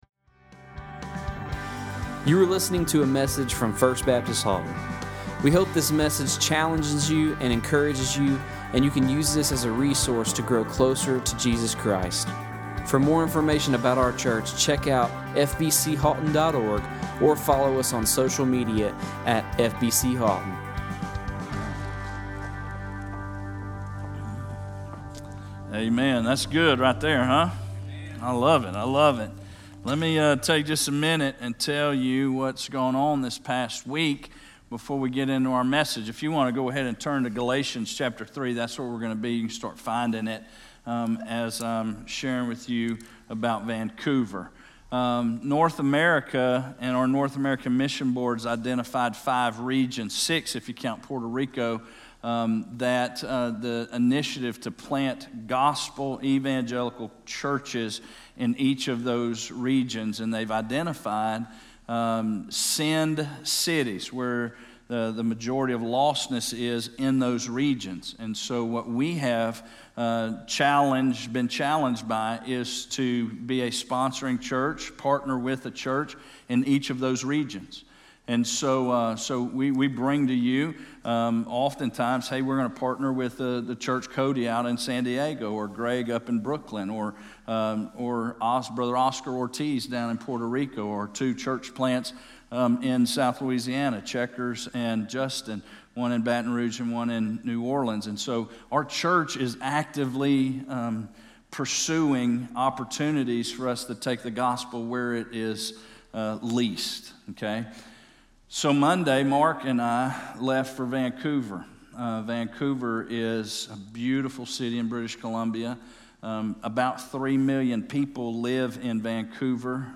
He Is Passage: Galatians 3:10-13 Service Type: Morning Worship